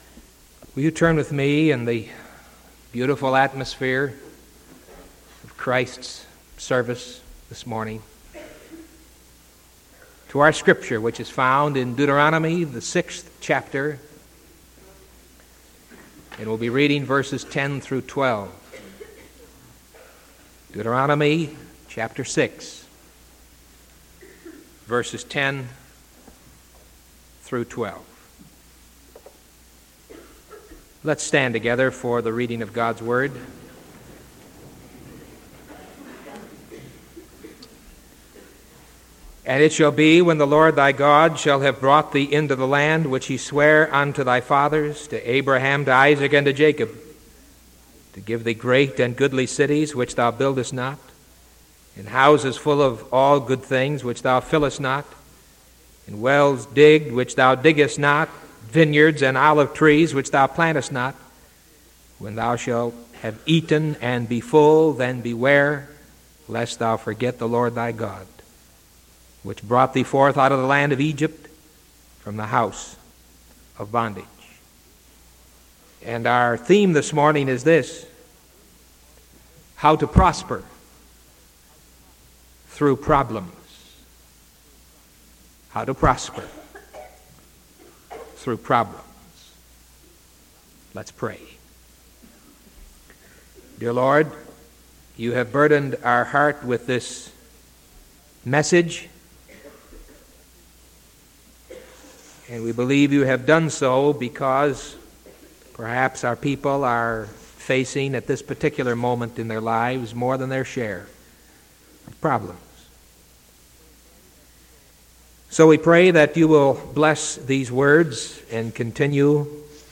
Sermon January 29th 1978 AM